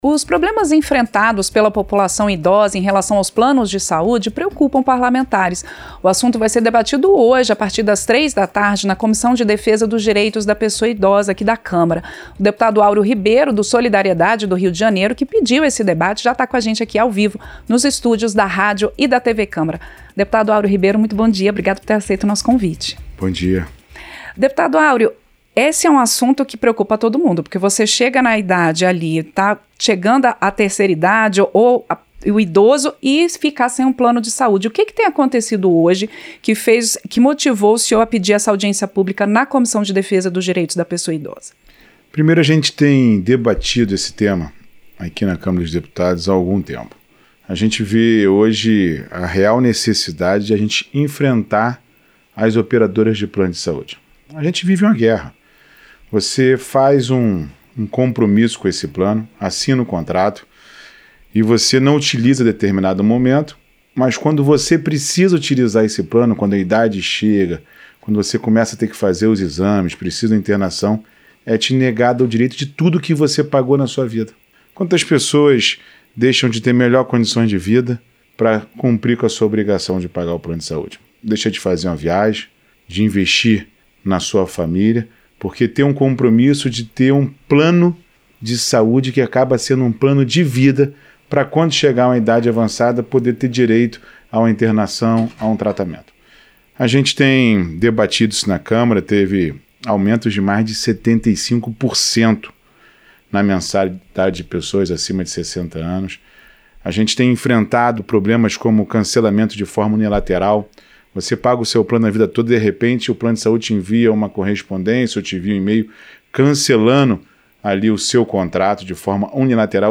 Entrevista - Dep. Aureo Ribeiro (SD-RJ)